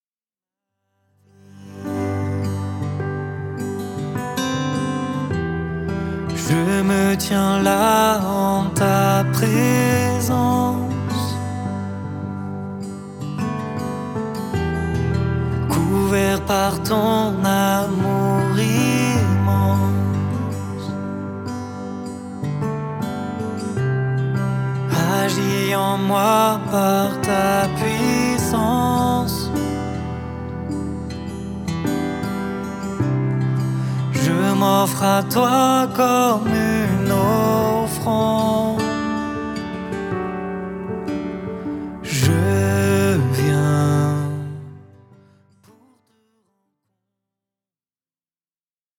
louanges